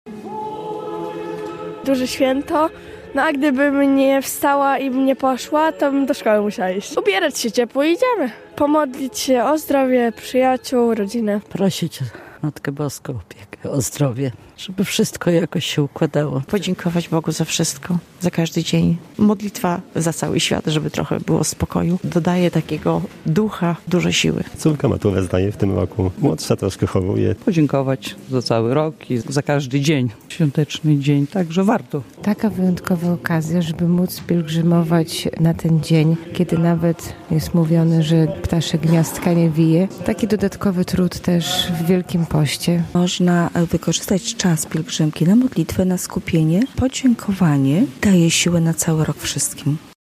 Wyruszyła pielgrzymka do monasteru w Supraślu - relacja